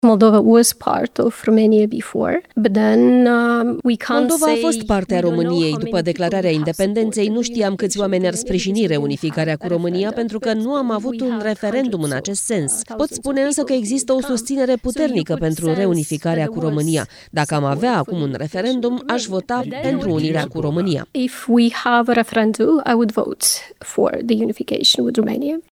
Președinta Republicii Moldova ar vota pentru reunificarea cu România dacă s-ar organiza acum un referendum. Maia Sandu a făcut aceste declarații într-un interviu pentru podcastul britanic „The Rest Is Politics: Leading”.
Președinta Republicii Moldova, Maia Sandu: „Dacă am avea acum un referendum, aș vota pentru reunirea cu România”